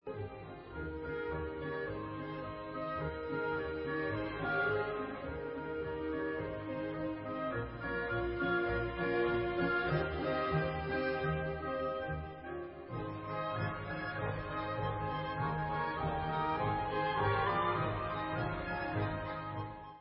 e moll (Allegretto scherzando) /Dumka